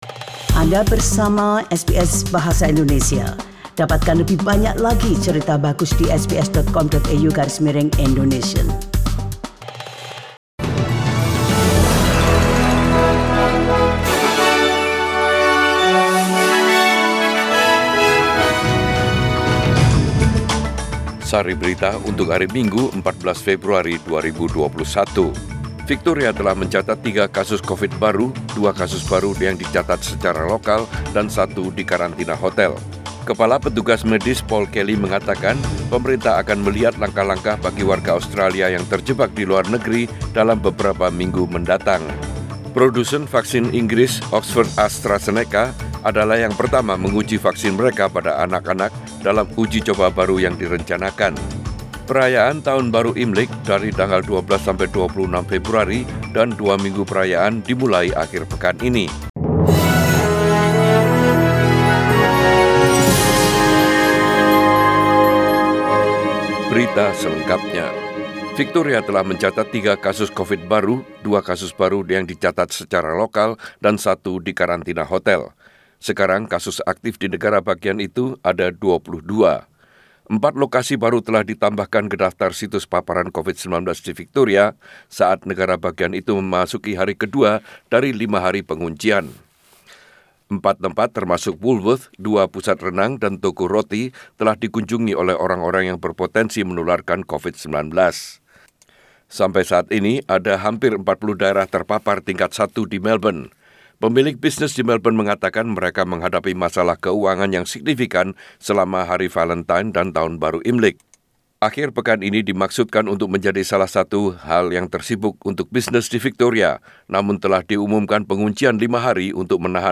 SBS Radio News in Bahasa Indonesia - 14 February 2021